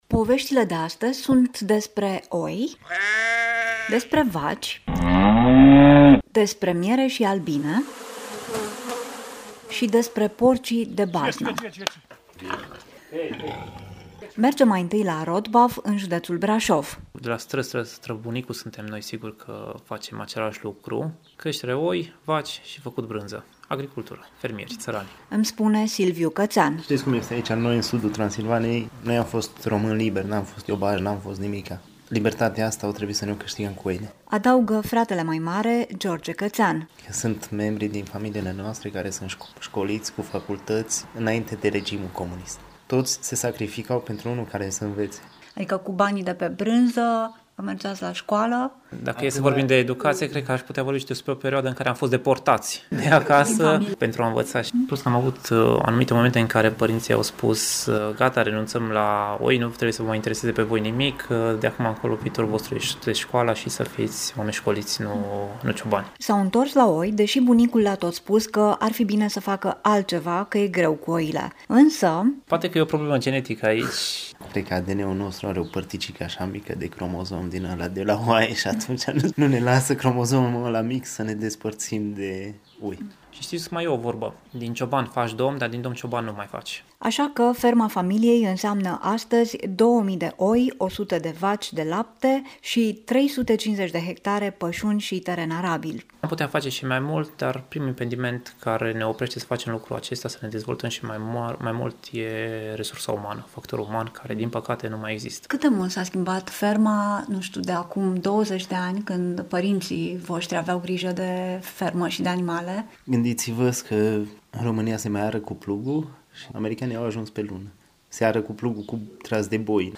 reportaj-Lumea-Europa-FM-3-iunie.mp3